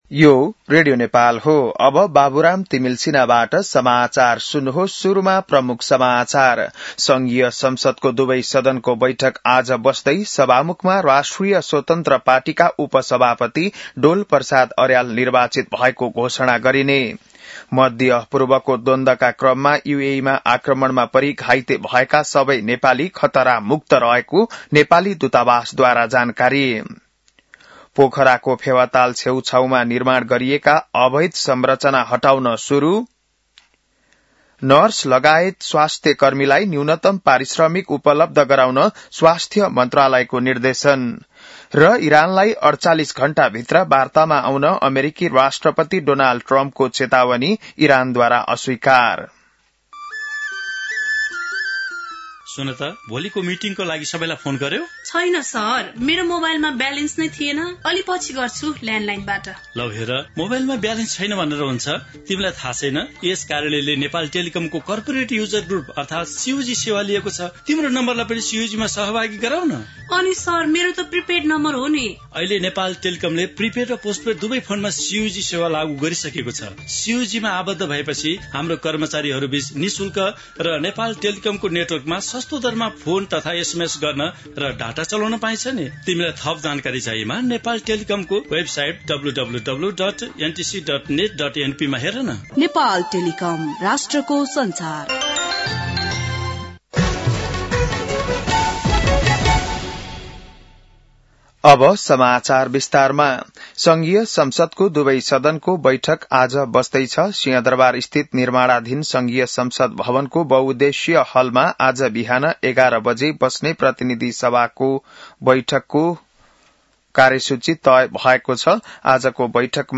An online outlet of Nepal's national radio broadcaster
बिहान ७ बजेको नेपाली समाचार : २२ चैत , २०८२